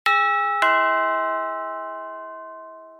Sound Effects
Quick Doorbell